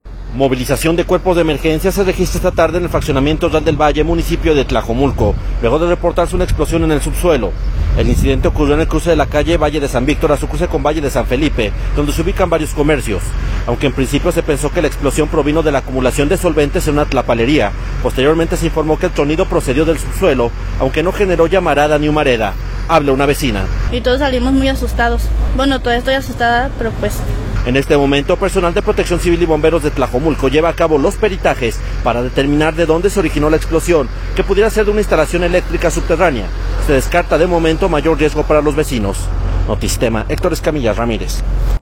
Habla una vecina.